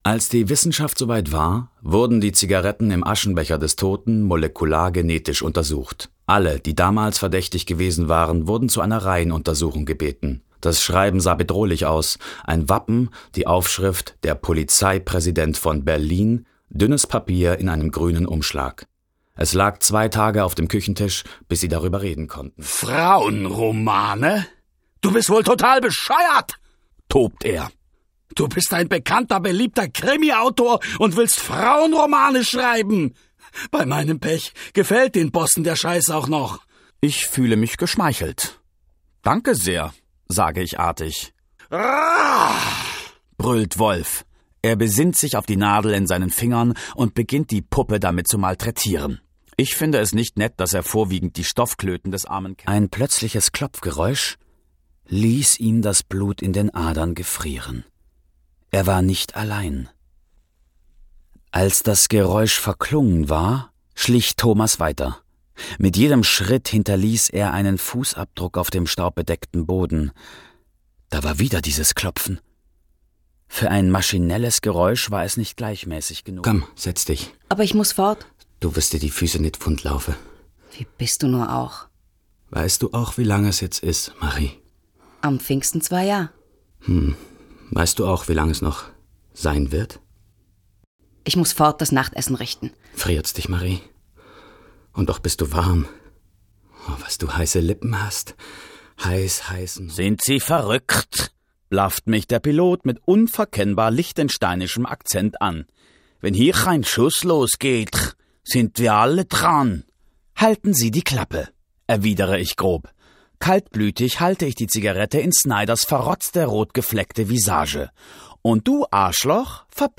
Sprecher & Schauspieler. 4 Jahre Sprech- und Schauspielausbildung am Max-Reinhardt-Seminar in Wien Mikrofonsprechen beim ORF Meine Stärken liegen sowohl in der Ruhe und im vertrauensvoll gewinnenden Ton als auch in der verspielten Gestaltung von Charakteren unterschiedlichster Couleur. Von ruhig bis schrill, von 30 bis 50.
Sprecher für Hörbuch, Werbung, Image, Off sonor, warm, souverän & facettenreich 30-50 Jahre
Sprechprobe: Sonstiges (Muttersprache):